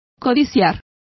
Complete with pronunciation of the translation of coveted.